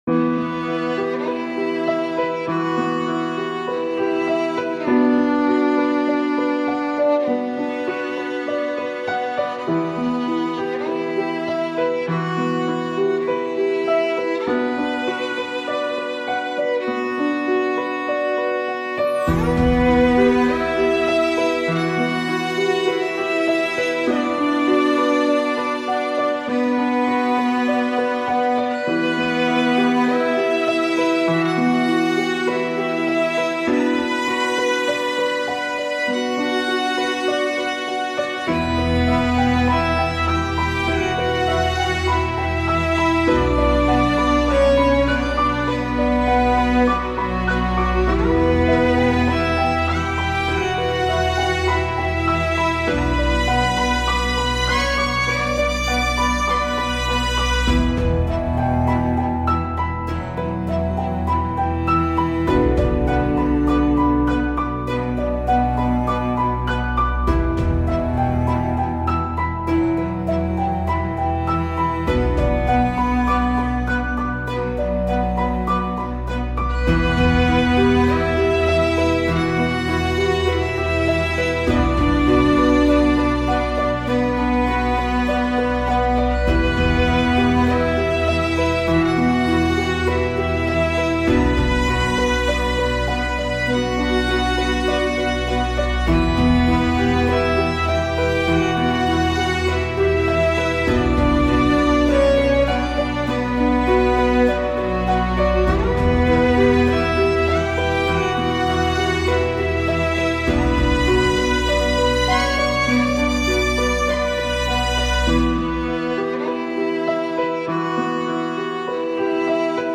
Immerse yourself in the melancholic beauty of